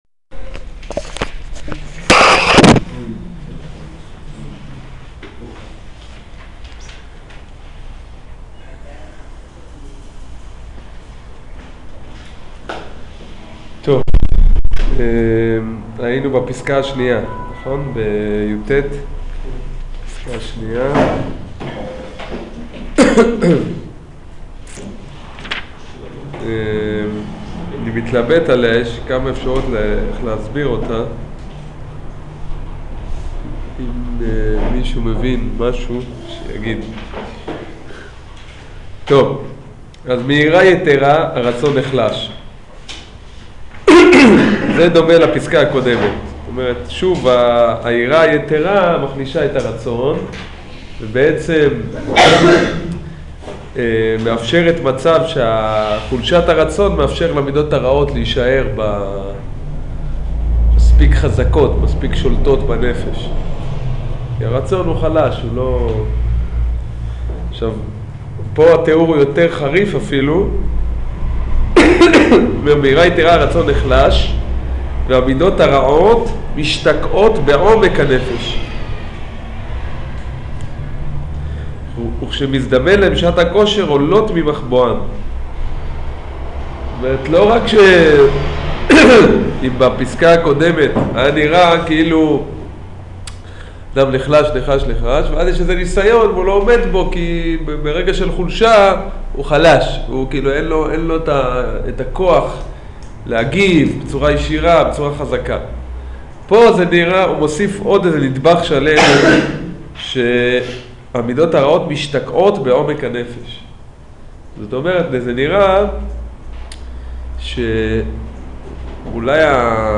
שיעור יראה יתרה ועצבות